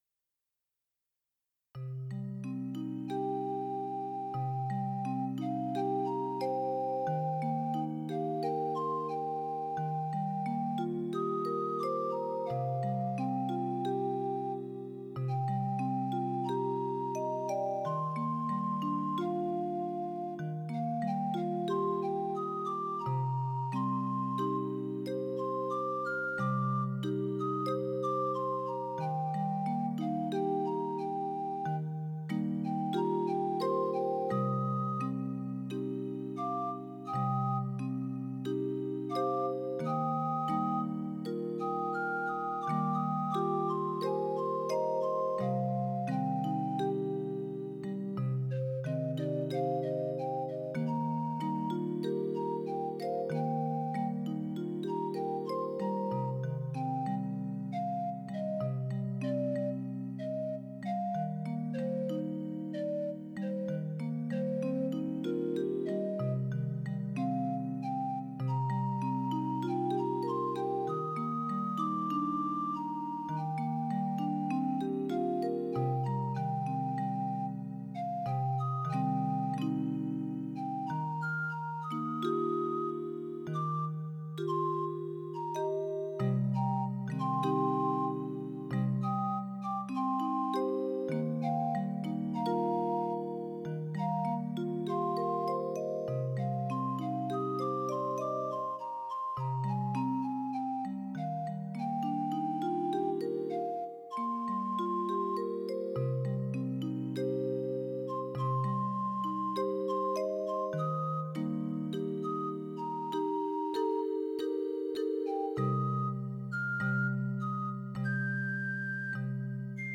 This was worked out on a Roland CM-32L and fullsize piano keyboard, improvised in two parts with the bells recorded as backing and the pipes put down on top.
bells-pipes.mp3